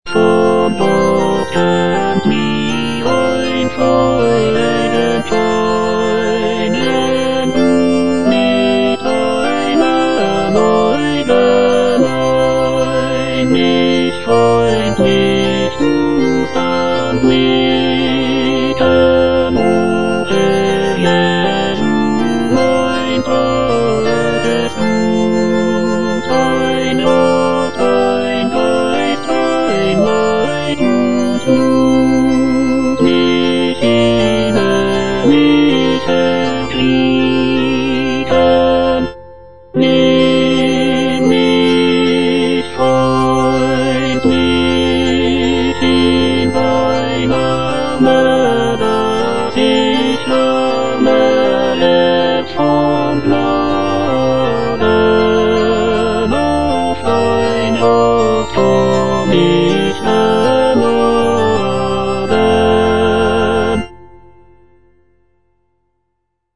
J.S. BACH - CANTATA "ERSCHALLET, IHR LIEDER" BWV172 (EDITION 2) Von Gott kömmt mir ein Freudenschein - Tenor (Emphasised voice and other voices) Ads stop: auto-stop